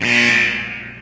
klaxon.ogg